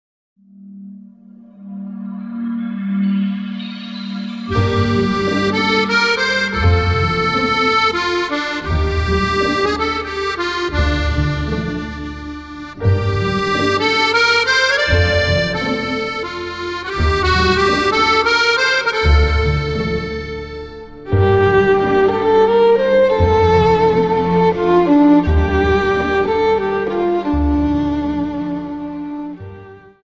Slow Waltz 29 Song